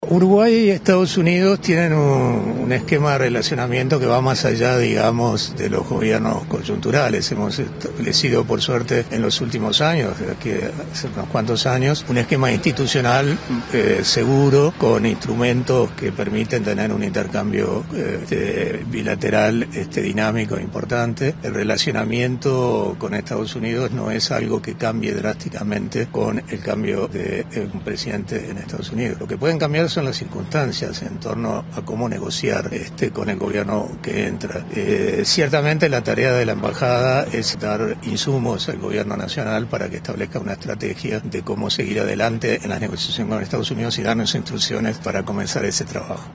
El embajador de Uruguay en EEUU, Carlos Gianelli, declaró a Canal 10 que la relación entre ambos países no va cambiar de manera "drástica".